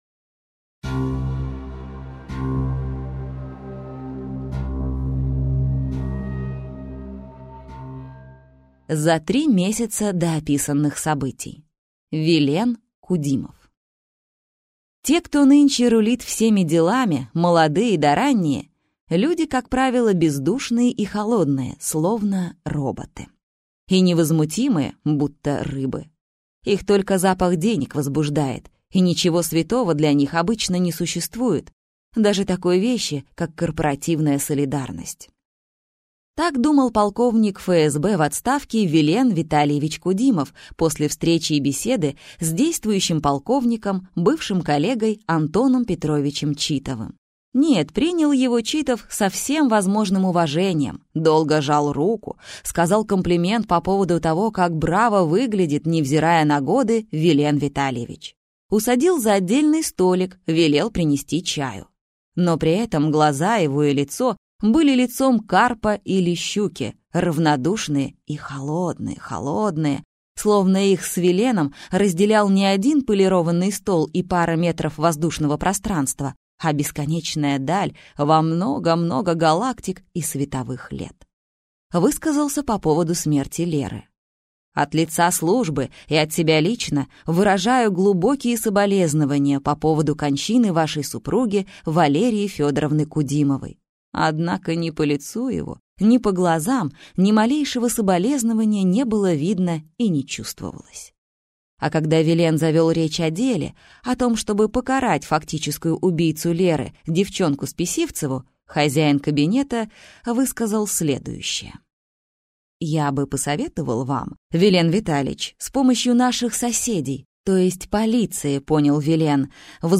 Прослушать фрагмент аудиокниги Здесь вам не Сакраменто Анна Литвиновы Произведений: 14 Скачать бесплатно книгу Скачать в MP3 Вы скачиваете фрагмент книги, предоставленный издательством